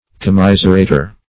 \Com*mis"er*a`tor\